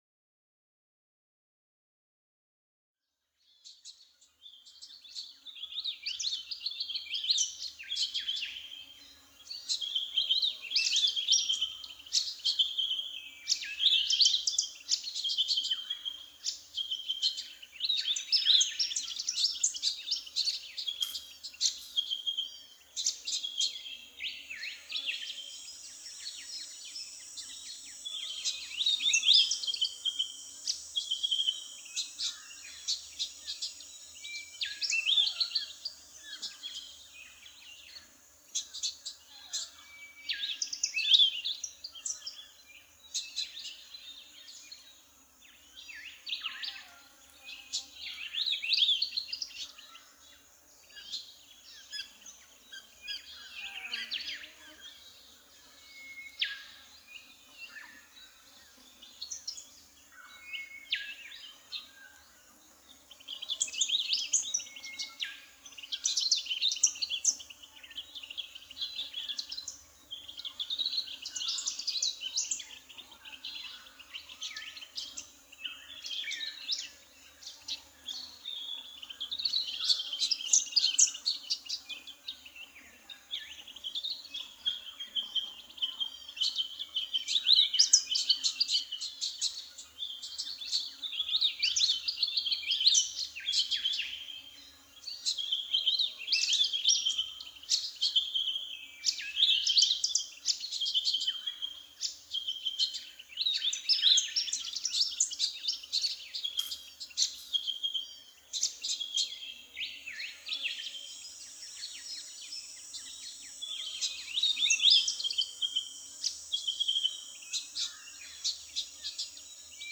AMB_Scene03_Ambience_L.ogg